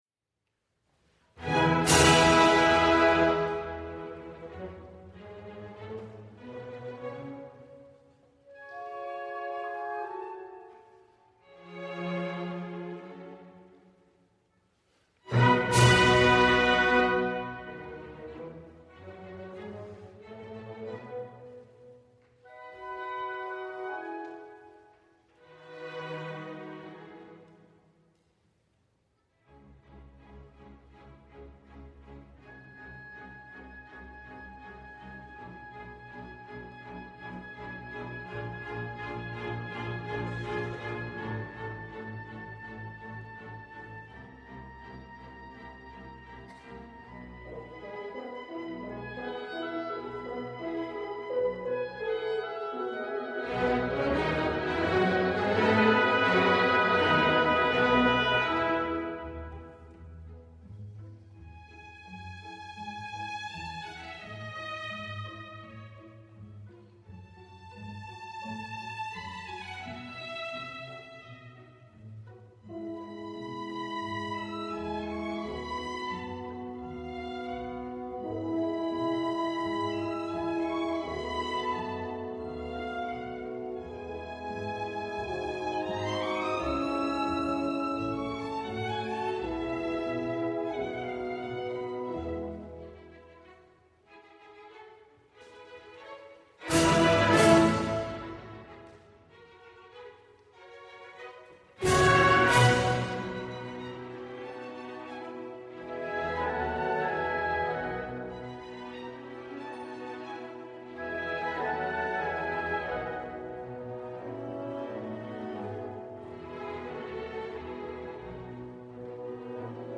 CONDUCTING AUDIO SAMPLES
Kennesaw State University Symphony